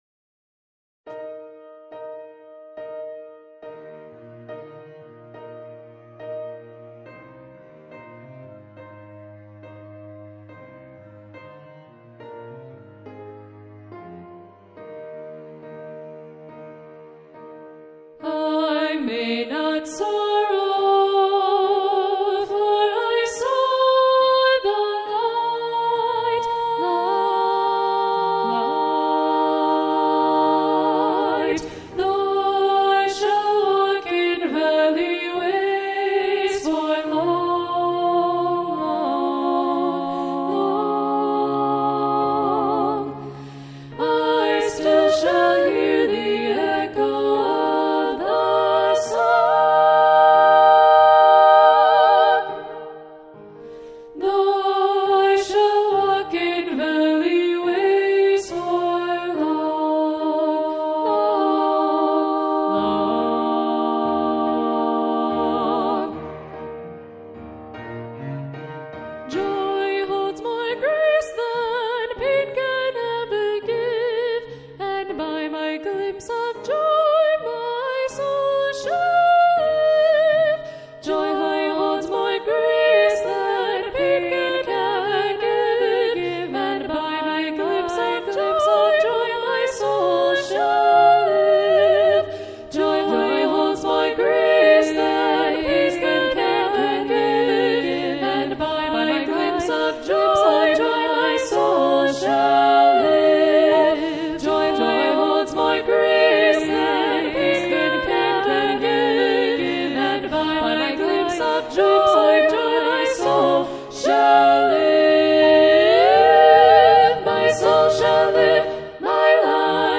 Voicing: "SSA"